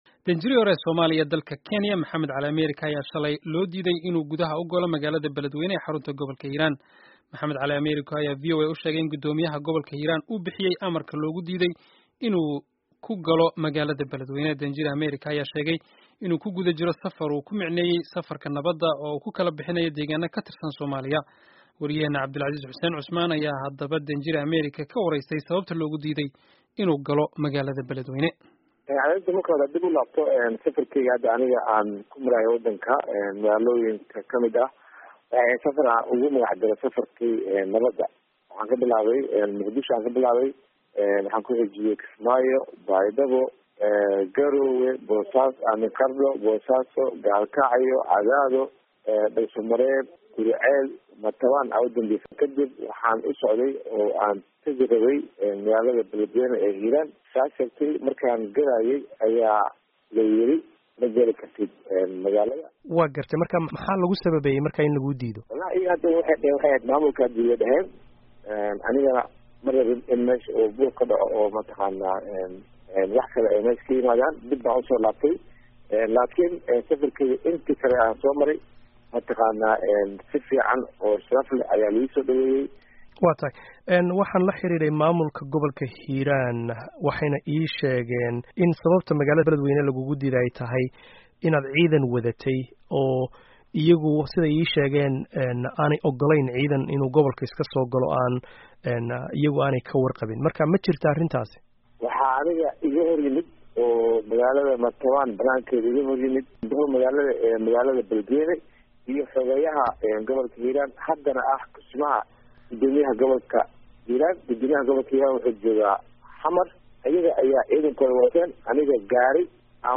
Dhageyso wareysiga M. C. Ameriko